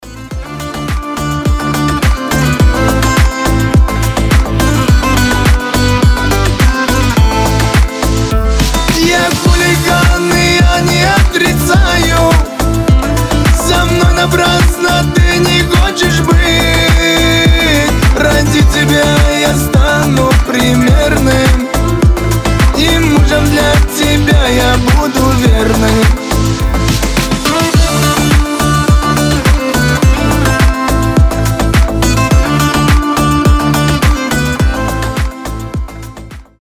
• Качество: 320, Stereo
гитара
кавказские